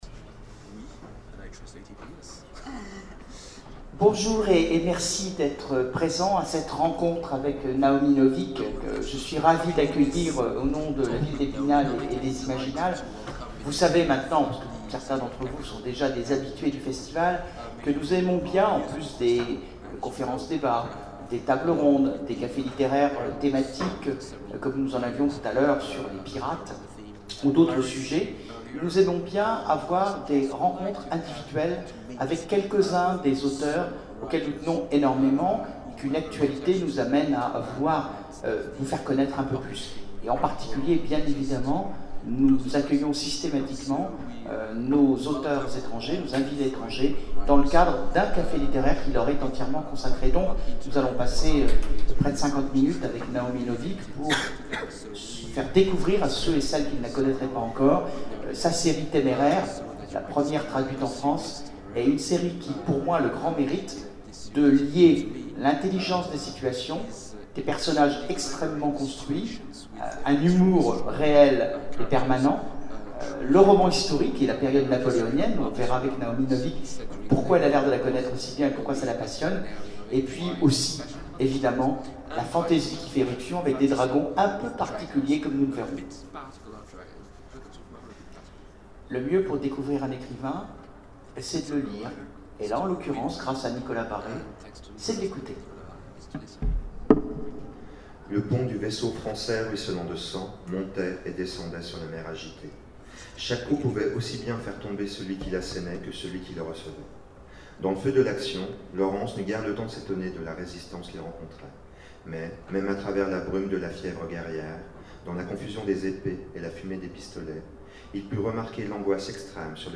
Imaginales 2012 : Conférence rencontre avec Naomi Novik
- le 31/10/2017 Partager Commenter Imaginales 2012 : Conférence rencontre avec Naomi Novik Télécharger le MP3 à lire aussi Naomi Novik Genres / Mots-clés Rencontre avec un auteur Conférence Partager cet article